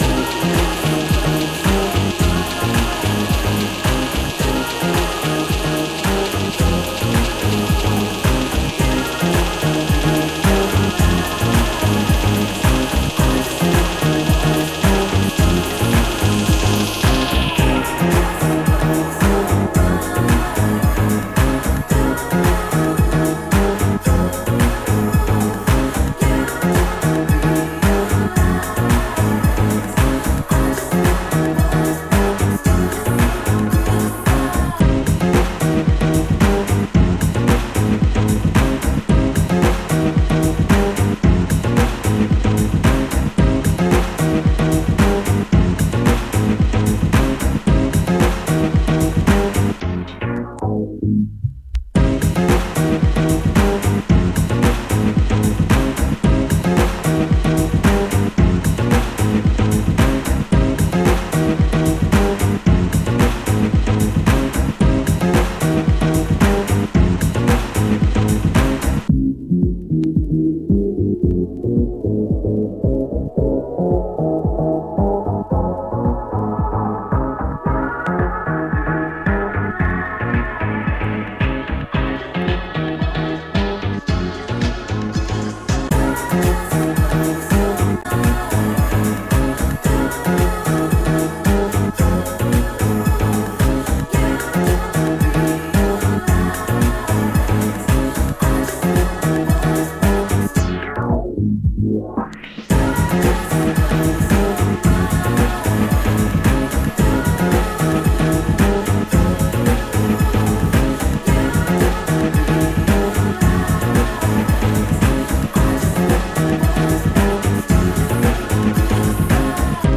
ウェアハウススタイルのDaphniaでしか体験出来ない確変RAGGA RAVE FEVER🎰777揃えに来てくだ_sai
録音エラーによる音飛びがあります